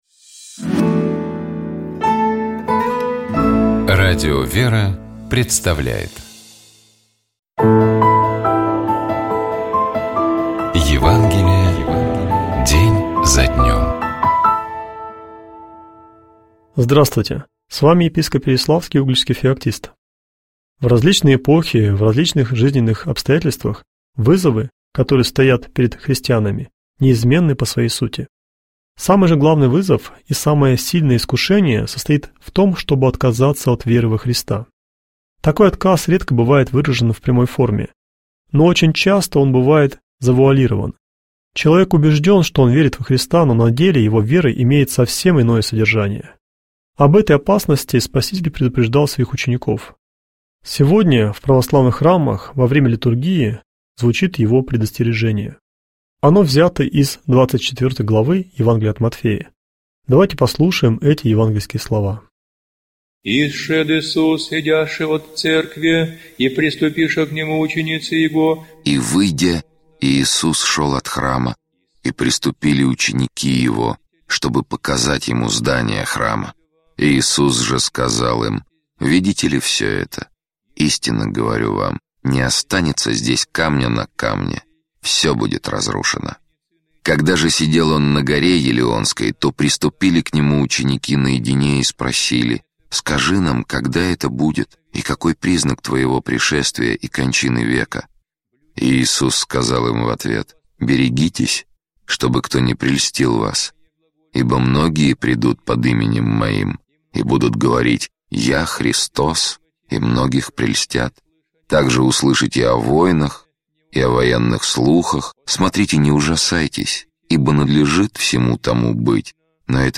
Читает и комментирует
епископ Феоктист ИгумновЧитает и комментирует епископ Переславский и Угличский Феоктист